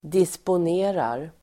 Uttal: [dispon'e:rar]